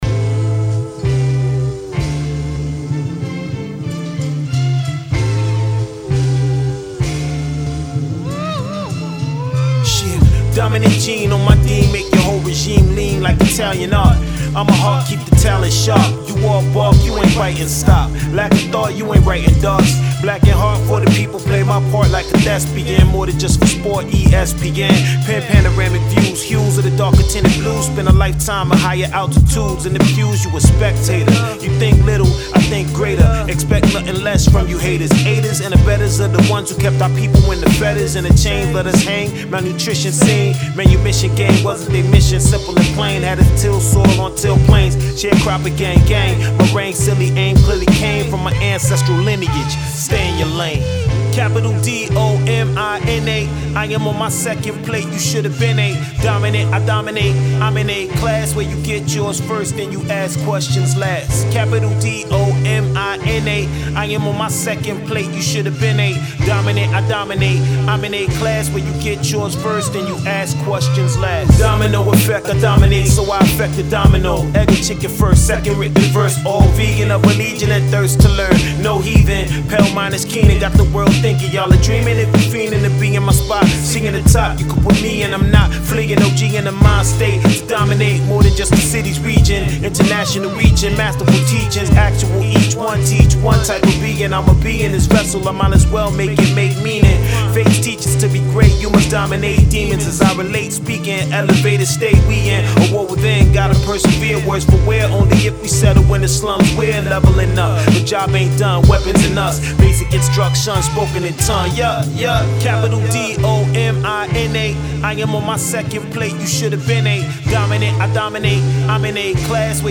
Your Source For Hip Hop News
matching the beat and lyrics perfectly.